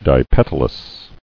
[di·pet·al·ous]